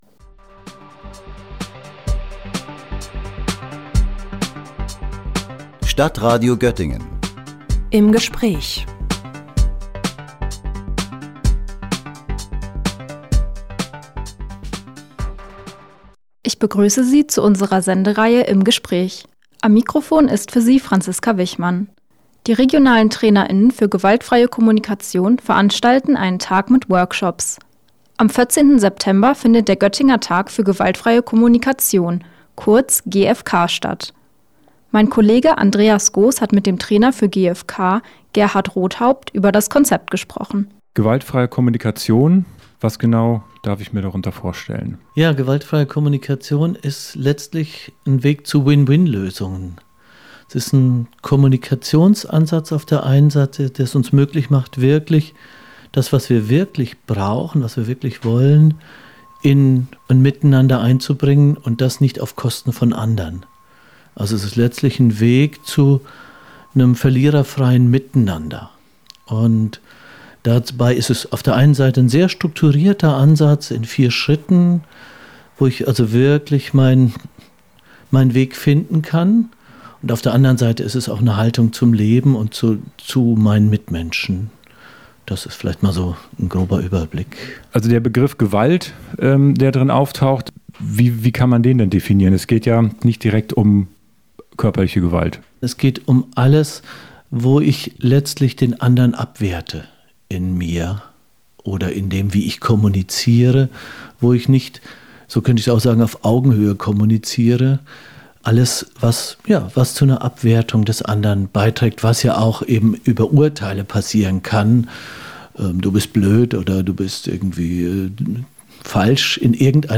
Gewaltfreie Kommunikation: Interview